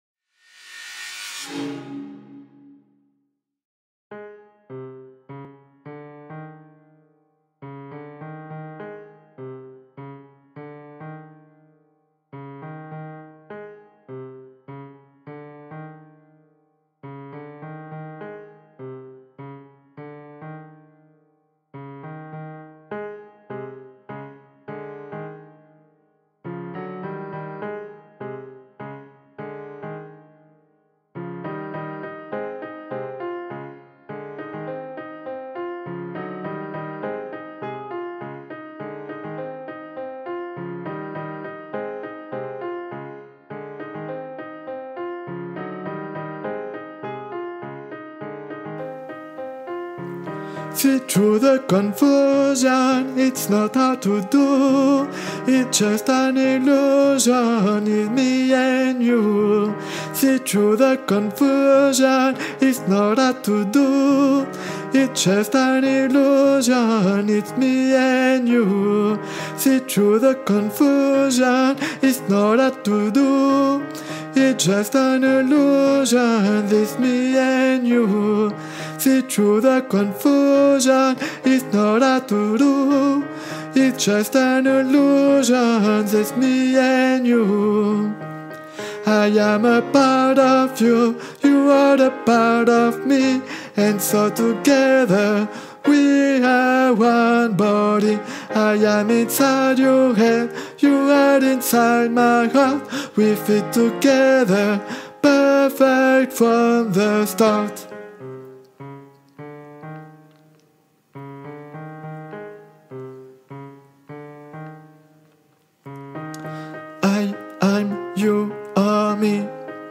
Guide Voix Sopranos (version chantée)